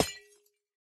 Minecraft Version Minecraft Version latest Latest Release | Latest Snapshot latest / assets / minecraft / sounds / block / copper_bulb / break2.ogg Compare With Compare With Latest Release | Latest Snapshot